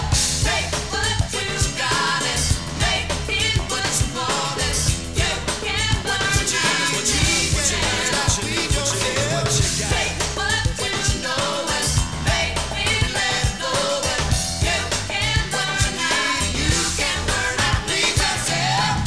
8 bit mono
From the Demo Tape